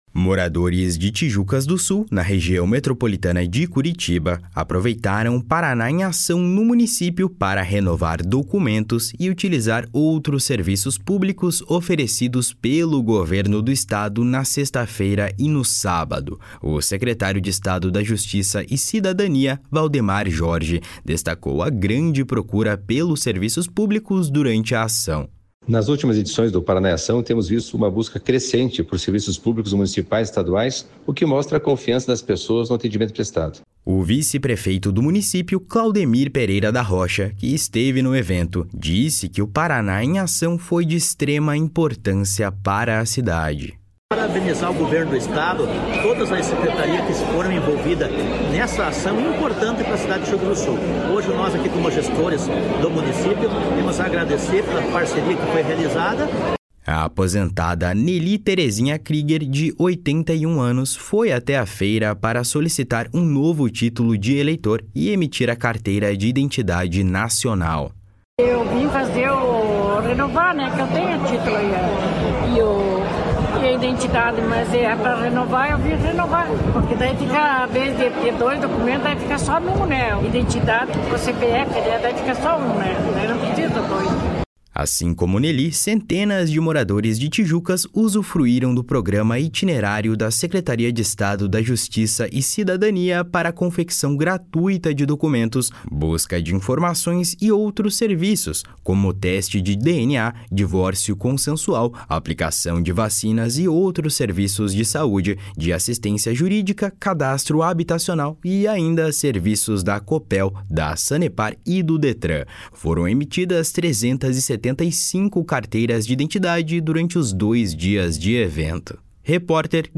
O secretário de Estado da Justiça e Cidadania, Valdemar Jorge, destacou a grande procura pelos serviços públicos durante a ação. // SONORA VALDEMAR JORGE //
O vice-prefeito Claudemir Pereira da Rocha, que esteve no evento, disse que o Paraná em Ação foi de extrema importância para a cidade. // SONORA CLAUDEMIR ROCHA //